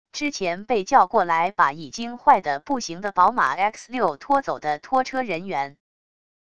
之前被叫过来把已经坏得不行的宝马x6拖走的拖车人员wav音频生成系统WAV Audio Player